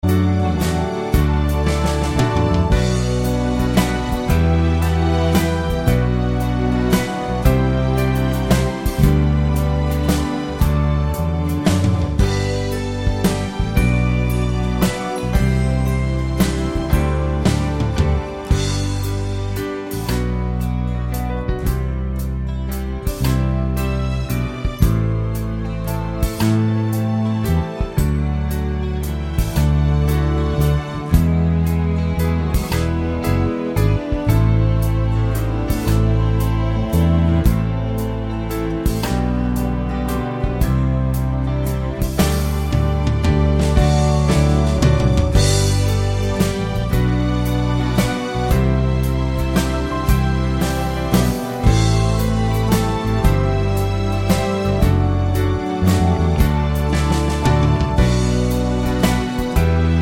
Intro Cut with No Backing Vocals Crooners 2:54 Buy £1.50